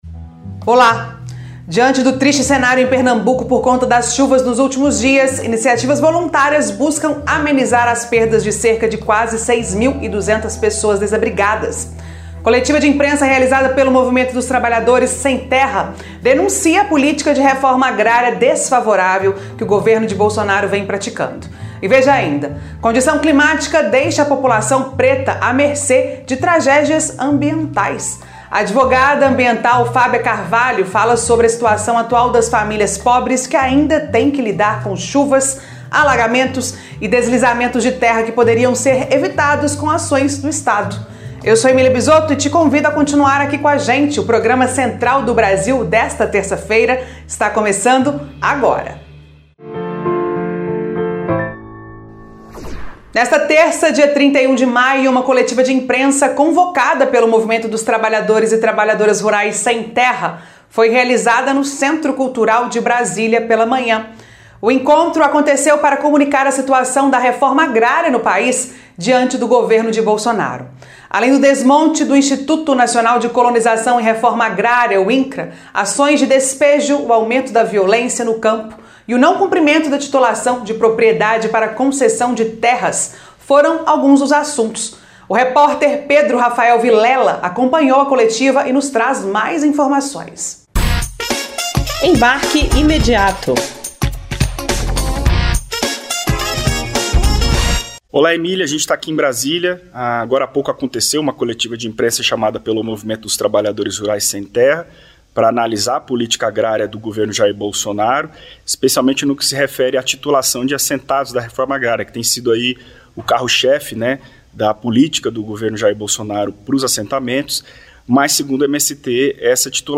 Na reportagem do quadro Nacional do programa Central do Brasil desta terça-feira (31), você acompanha alguns dos vários projetos que estão apoiando os atingidos.